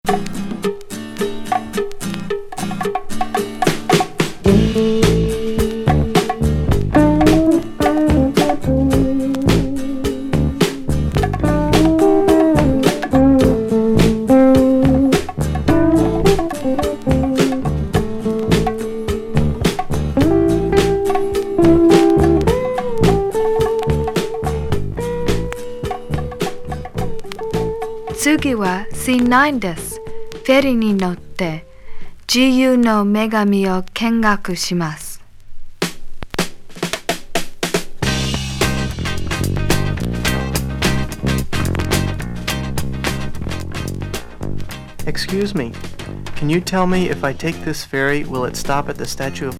等の和ファンク＋英会話レッスンLP!